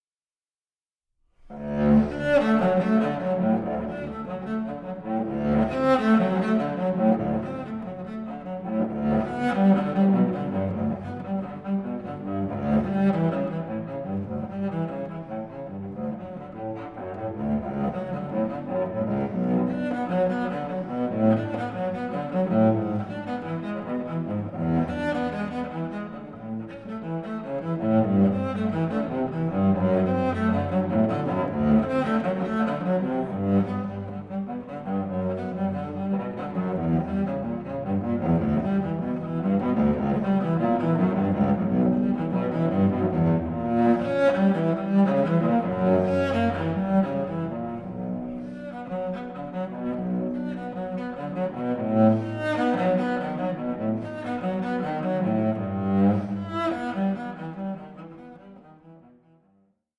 baroque cello and cello piccolo